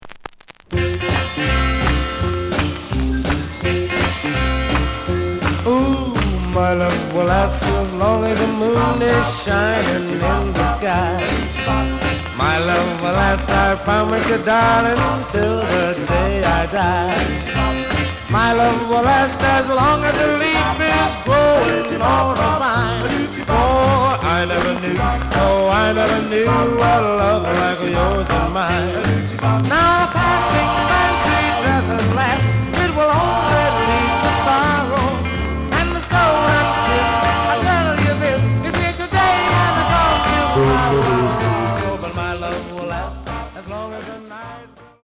The audio sample is recorded from the actual item.
●Genre: Rhythm And Blues / Rock 'n' Roll
Some click noise on B side due to scratches.)